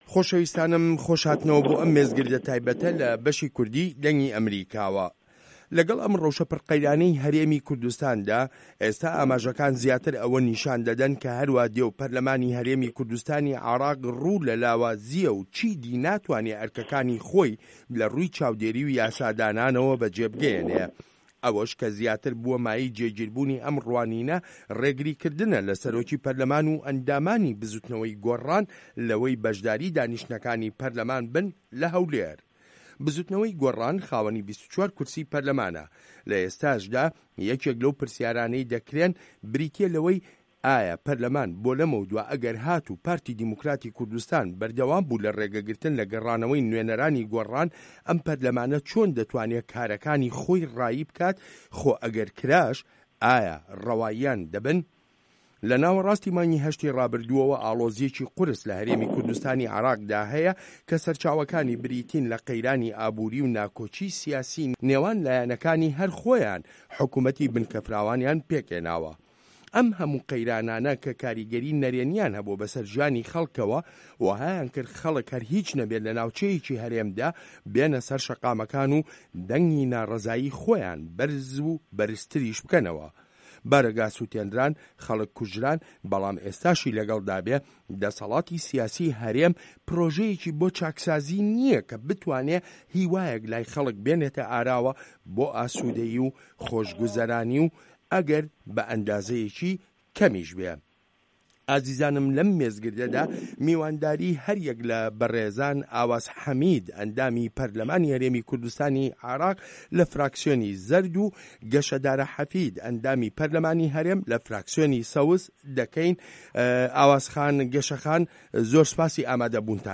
مێزگرد : پەرلەمان و قەیرانەکانی هەرێم